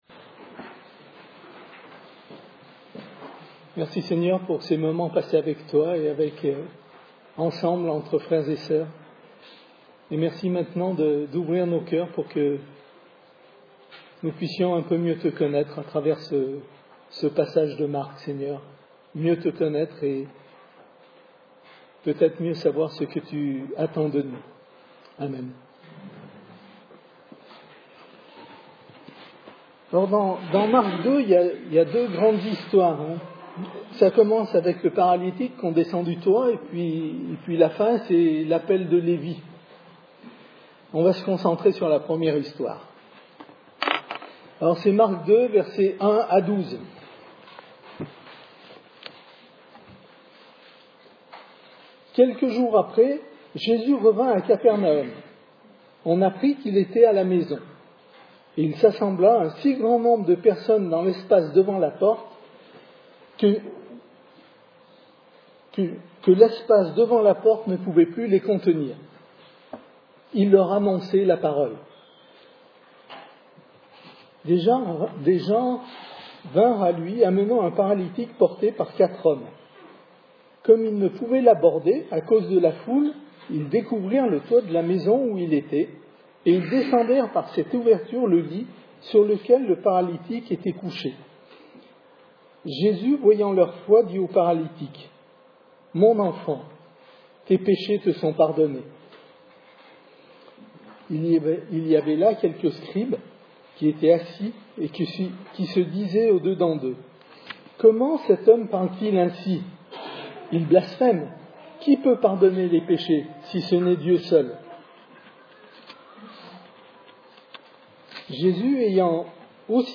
Type De Service: Culte Dimanche